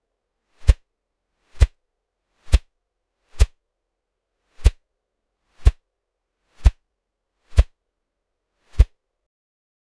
Clean, no background noise. 0:10 a massive ocean wave destroying a ship 0:10 swing puño m1 fuerte pero suave grave intermedio pero suave muy suave como golpe rapido dejando pequeña onda de aire 0:10
swing-puo-m1-fuerte-pero-jkmpuoip.wav